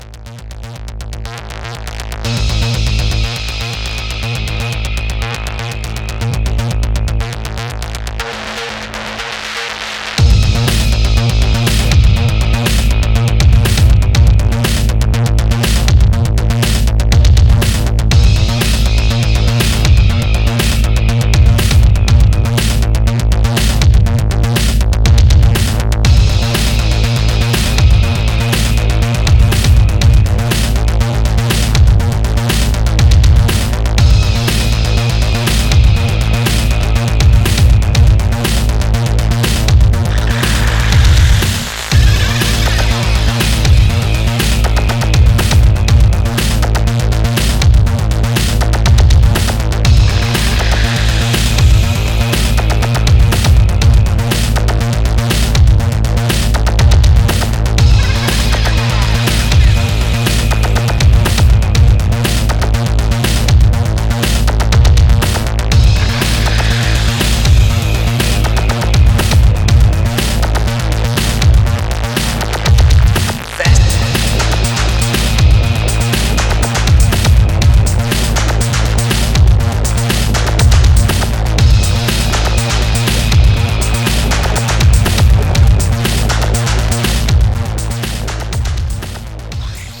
EBM/Industrial